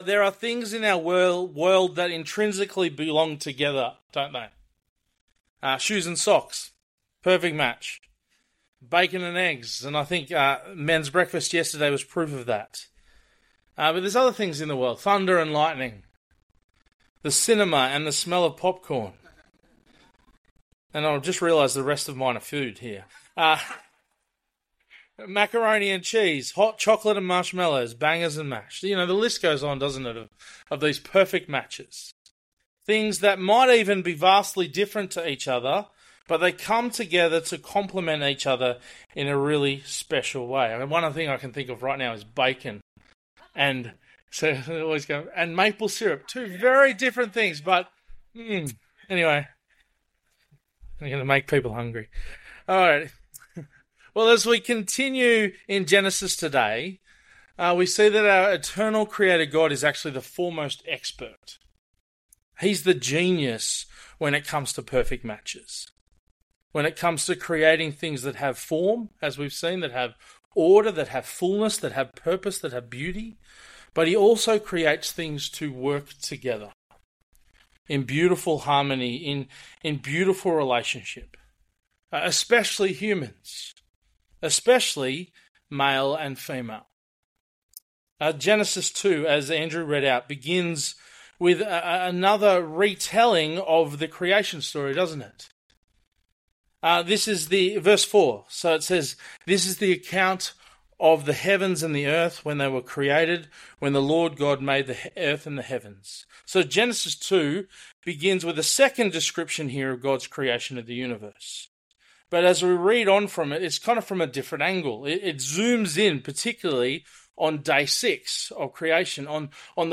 Bible Talks | Bairnsdale Baptist Church